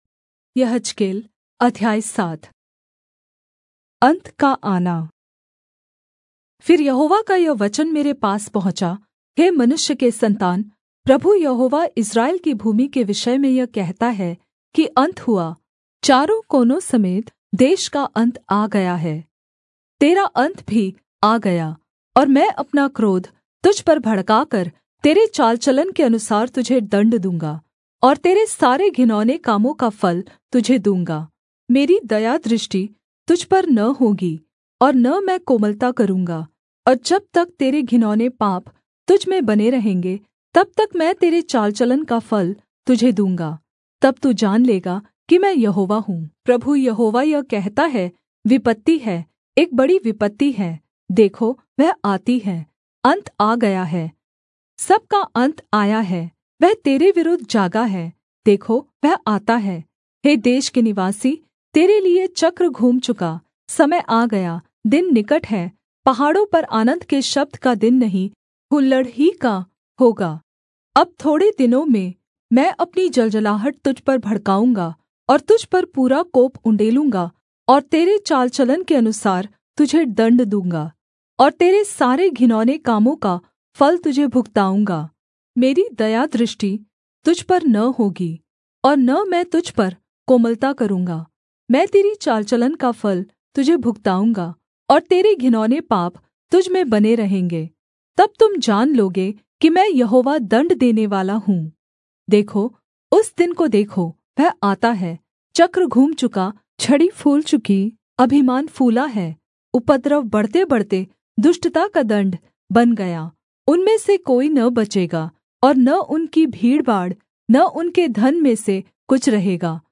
Hindi Audio Bible - Ezekiel 30 in Irvhi bible version